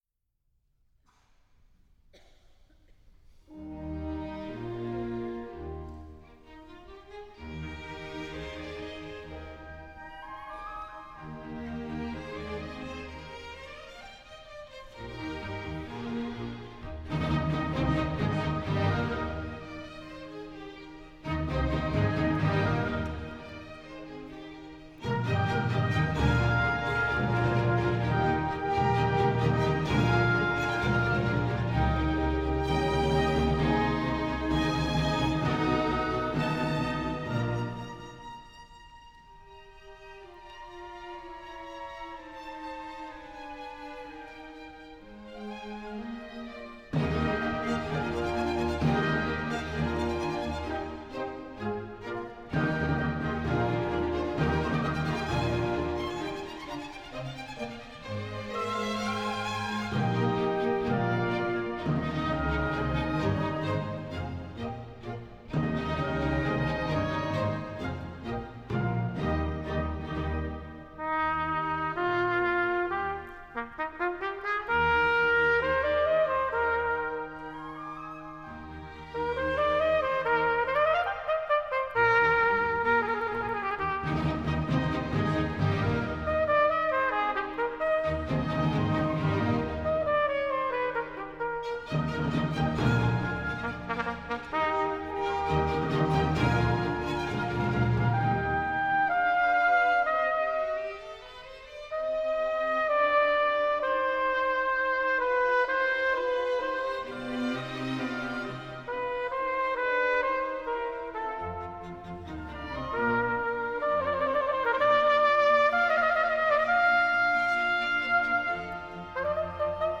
Joseph Haydn: Concierto para trompeta y Orquesta de Cuerda en mi bemol mayor HOB.VII:1 | Euskadiko Orkestra - Basque National Orchestra
Joseph Haydn: Concierto para trompeta y Orquesta de Cuerda en mi bemol mayor HOB.VII:1